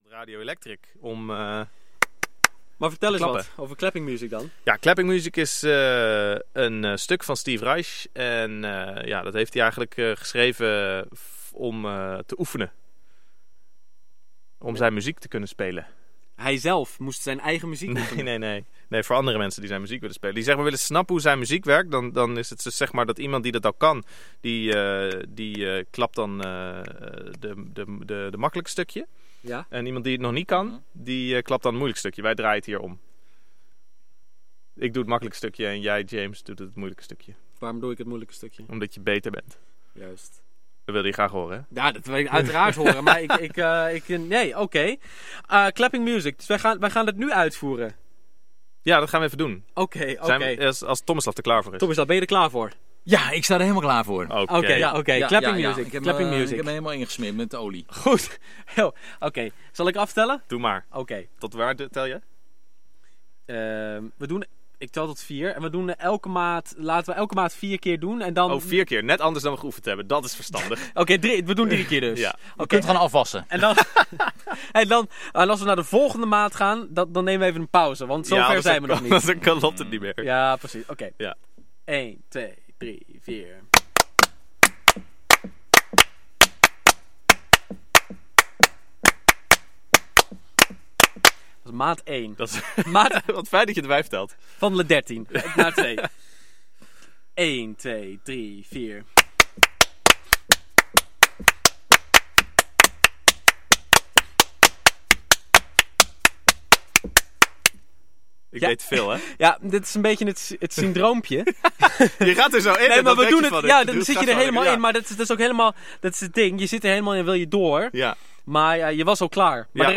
Eerst als duo
clapping in de studio!